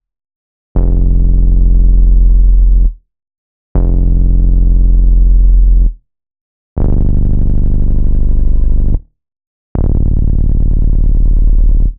Index of /DESN275/loops/Loop Set - Aerosol - Ambient Synth Loops - F and Dm/Loops
Fireflies_80_F_Bass.wav